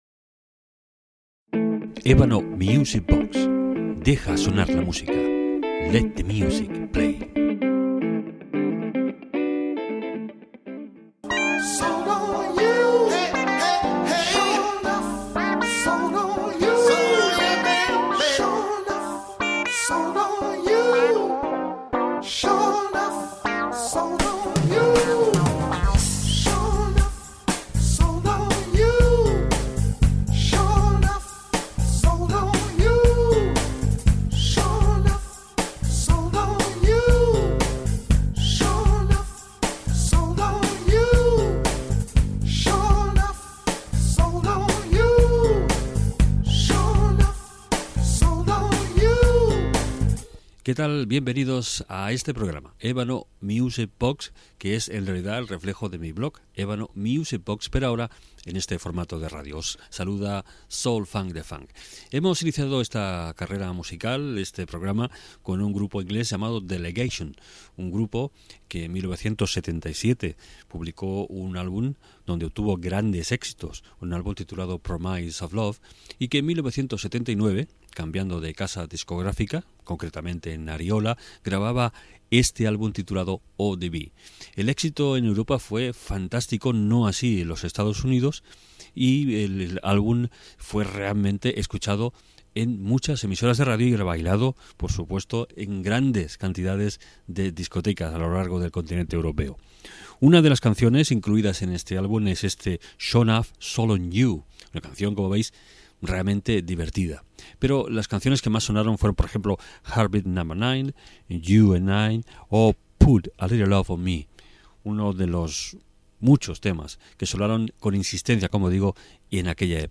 Indicatiu de la ràdio, tema musical i dades del grup Delegation
Musical